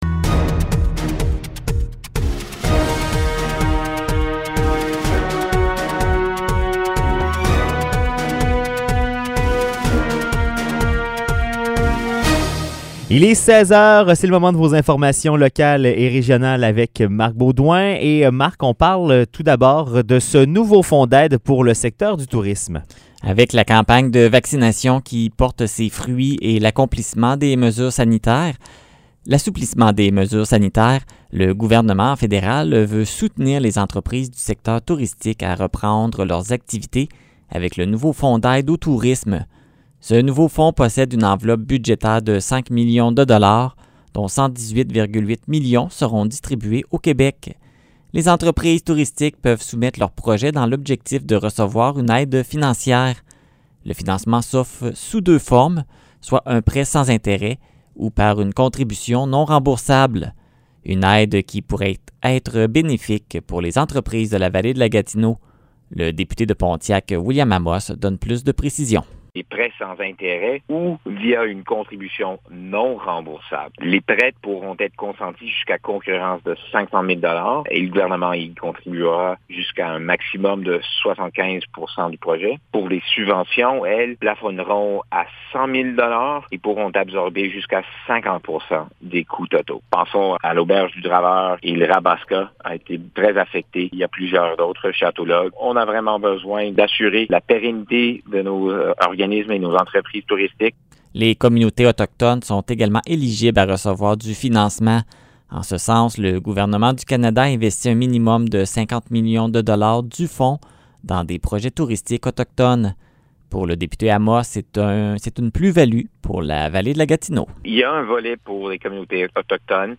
Nouvelles locales - 22 juillet 2021 - 16 h